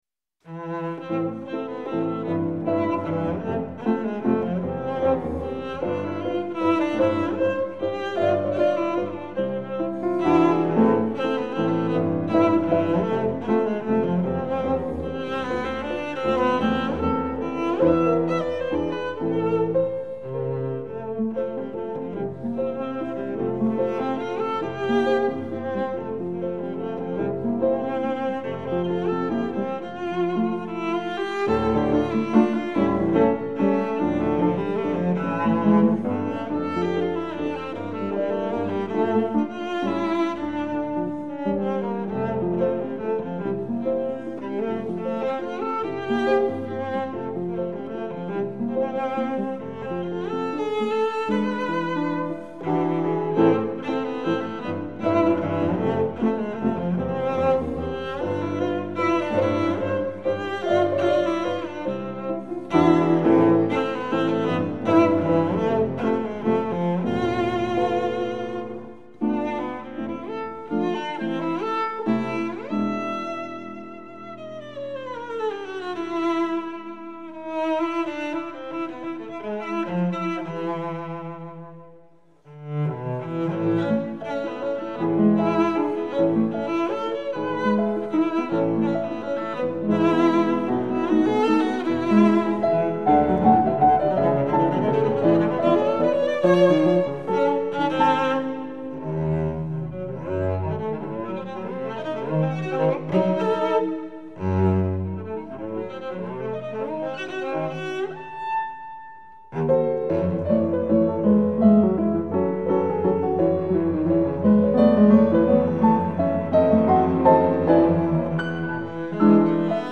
The pieces are inthe Romantic style.